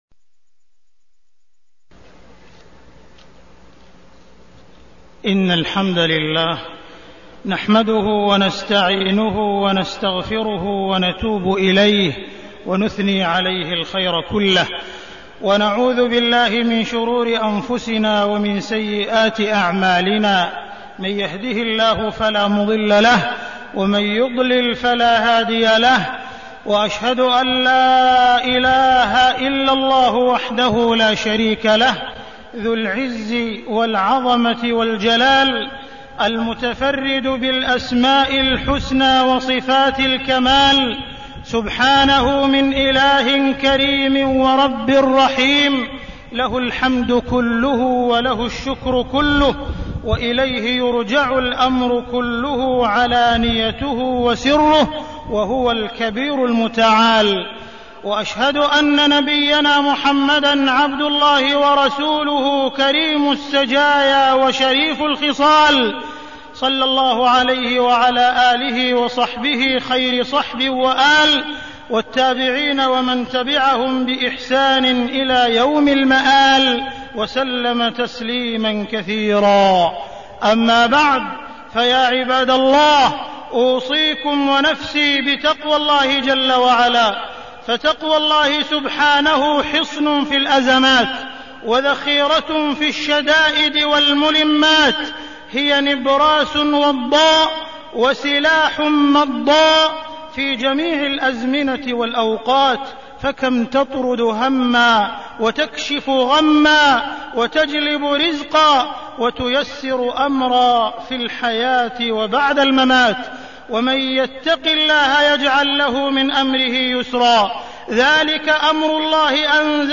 تاريخ النشر ٦ جمادى الأولى ١٤١٩ هـ المكان: المسجد الحرام الشيخ: معالي الشيخ أ.د. عبدالرحمن بن عبدالعزيز السديس معالي الشيخ أ.د. عبدالرحمن بن عبدالعزيز السديس ظاهرة الإرهاب